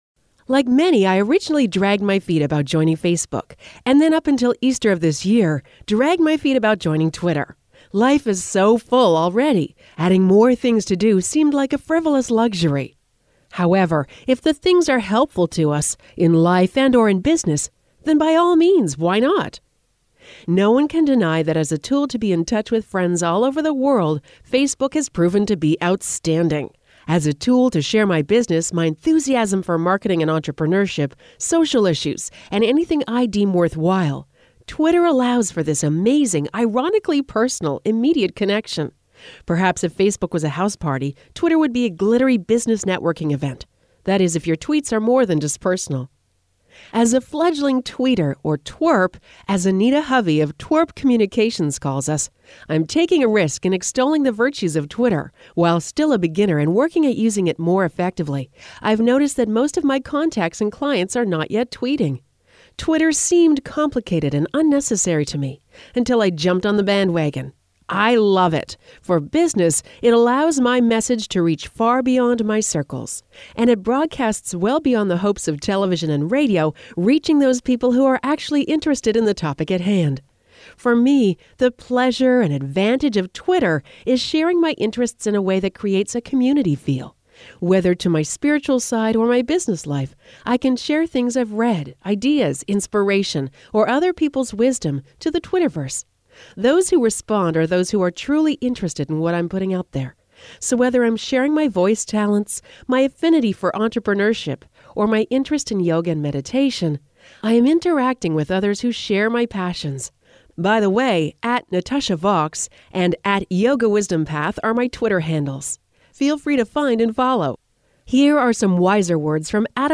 Rockin' Robin* (audio version of blog below) Like many, I originally dragged my feet about joining Facebook and then, up until Easter of this year, dragged my feet about joining Twitter.